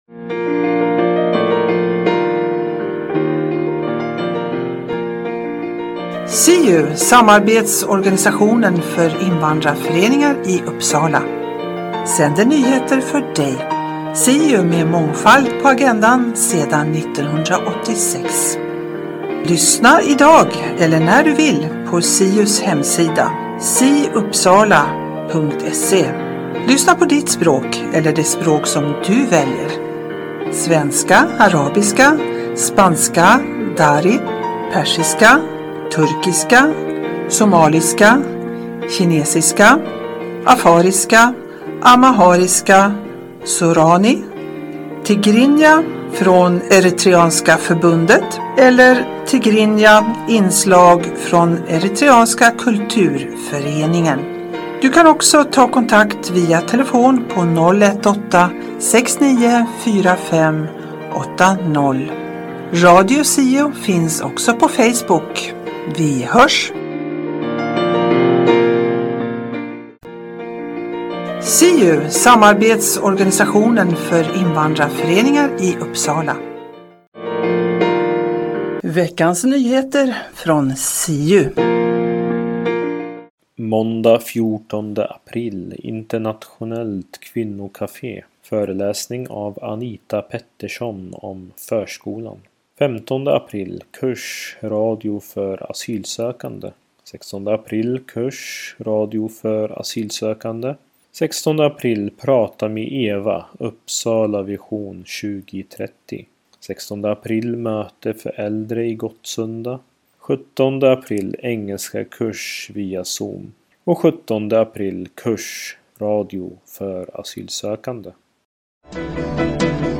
Siu-programmet på svenska innehåller SIU:s nyheter, Nyheter Uppsala och Riksnyheter. Berika din fritid med information och musik.